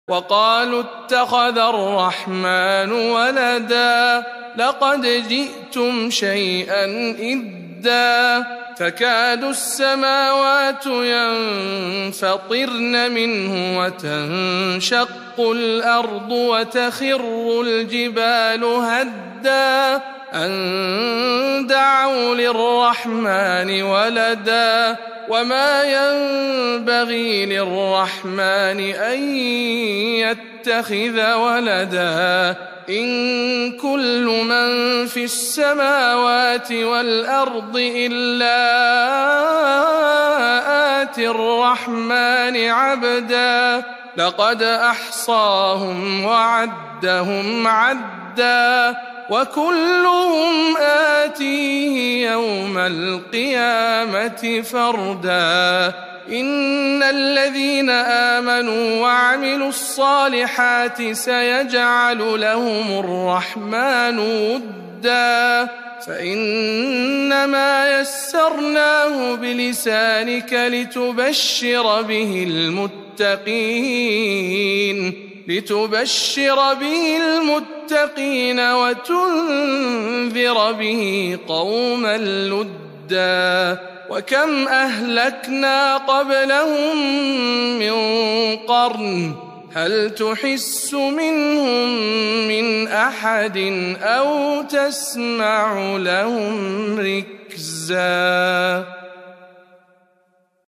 تلاوة مميزة من سورة مريم